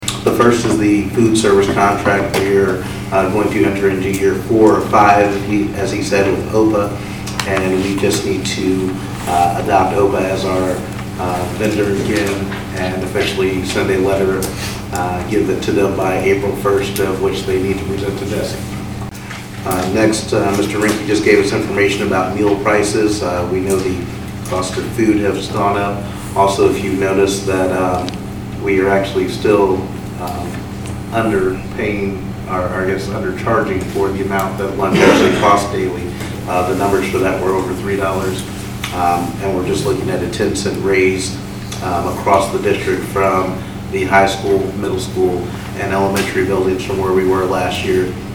Board President Bryon Jacques gave details on both action items on the agenda.